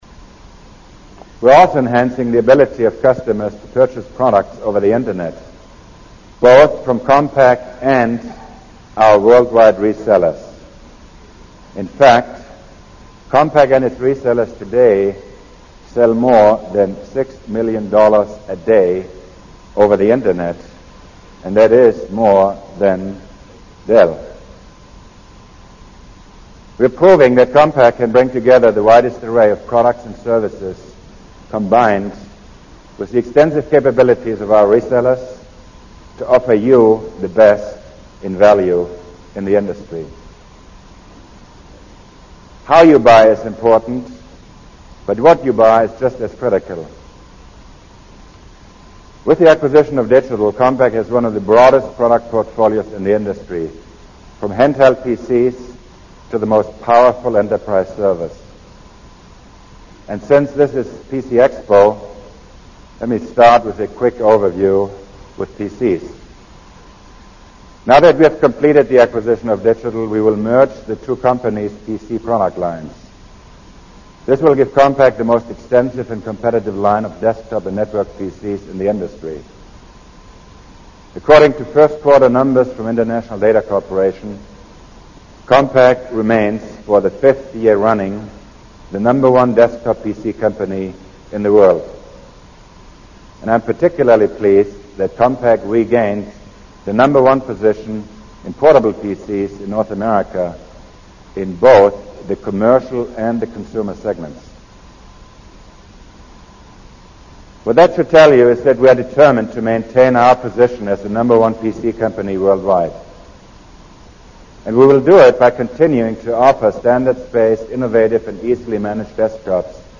财富精英励志演讲42：提高我们的能力,满足您的要求(9) 听力文件下载—在线英语听力室